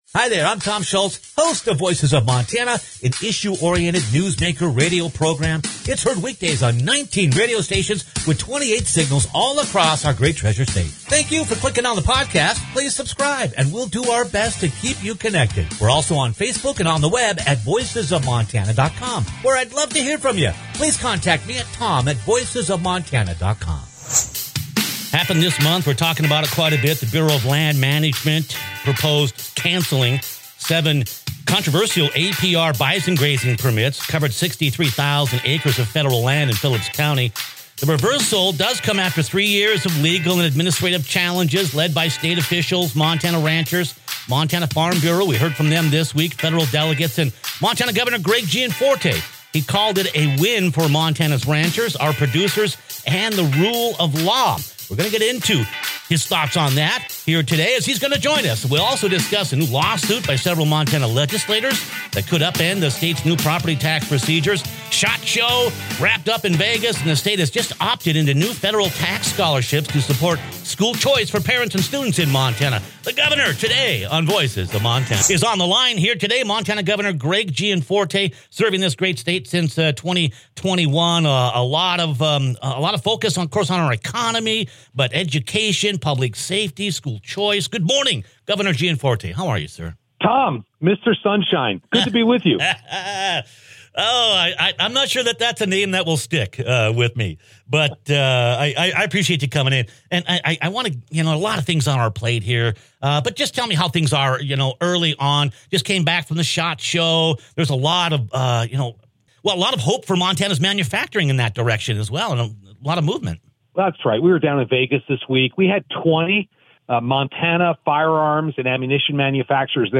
The Governor's office has been opposed to the controversial grazing permits and variances awarded the "non-profit" American Prairie, which has purchased roughly $100 million in Montana Ranch land since 2012. Click on the podcast as Governor Gianforte discusses why the case was so important to state and the future of production agriculture.